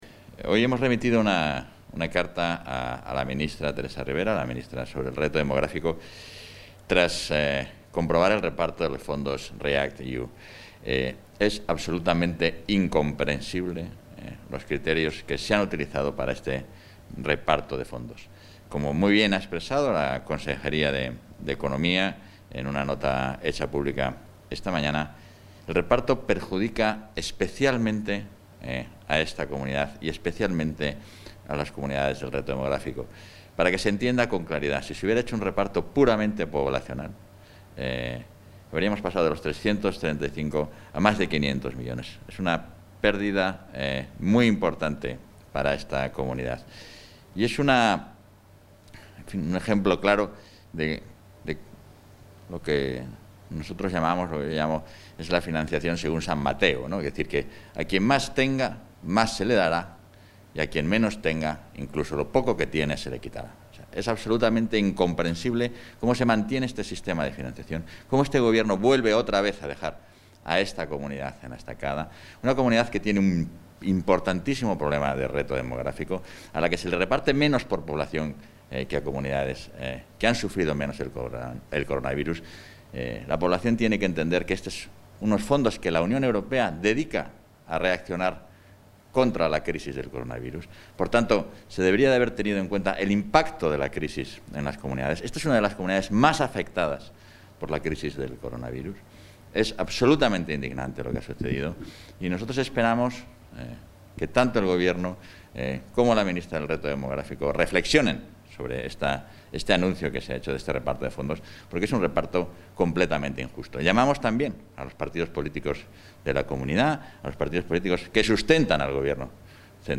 Declaraciones del vicepresidente Igea en relación con el reparto de los fondos REACT UE y la asignación de recursos a Castilla y León